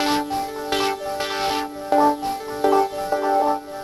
tx_synth_125_sliver_DAG.wav